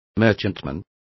Complete with pronunciation of the translation of merchantmen.